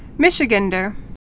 (mĭshĭ-gănder)